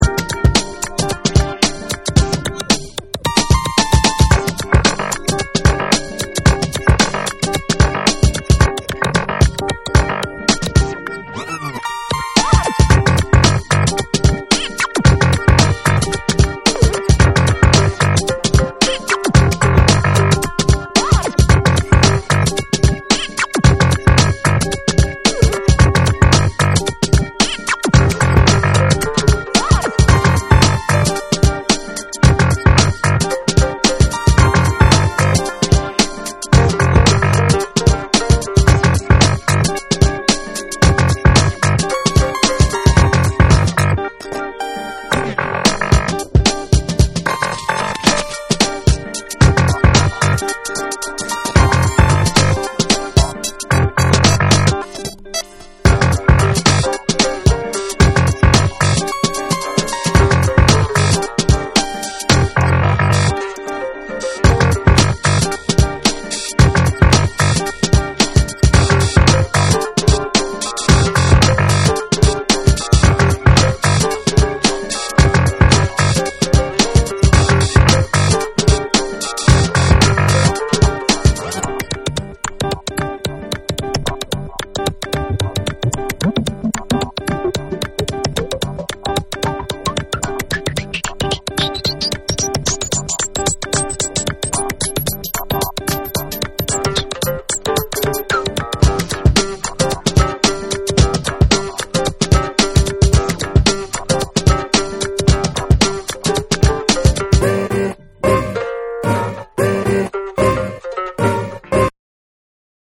BREAKBEATS / CHILL OUT